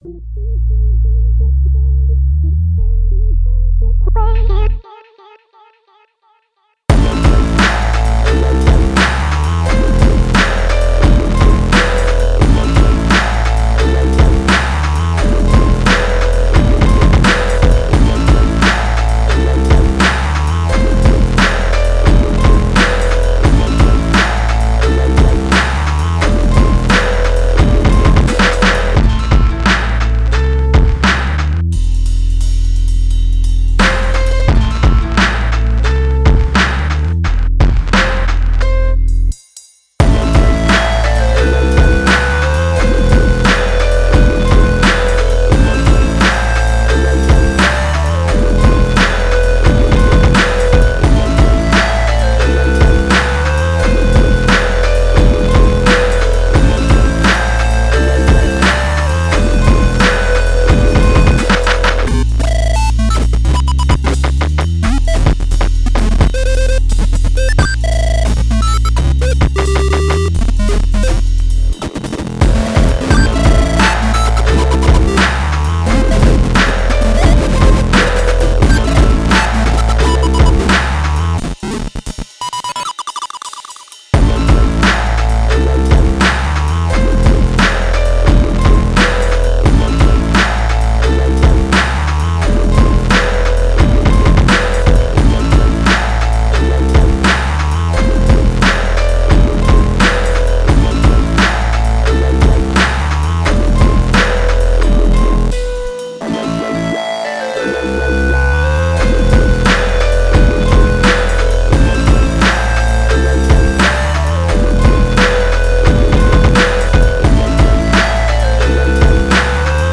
Raw slow bad boy anthem with melancholic synth line.